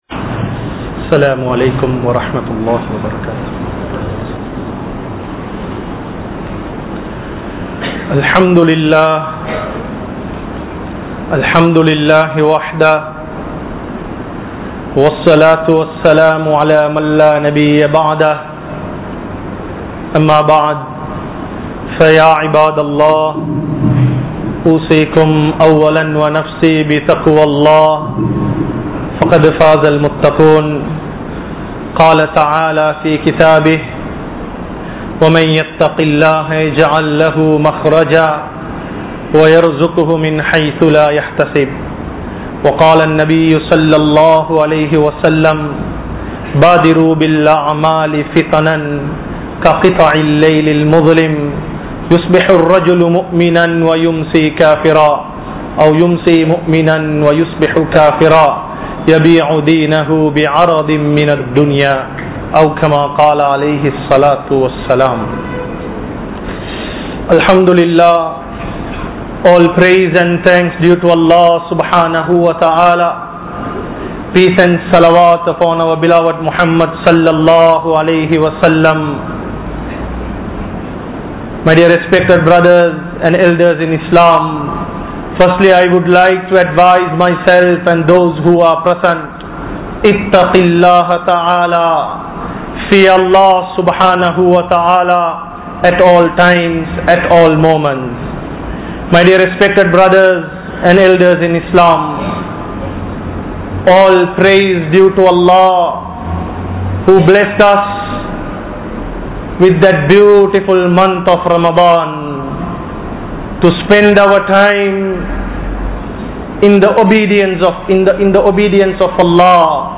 Signs of Kiyamah | Audio Bayans | All Ceylon Muslim Youth Community | Addalaichenai